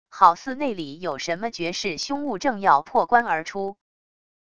好似内里有什么绝世凶物正要破棺而出wav音频生成系统WAV Audio Player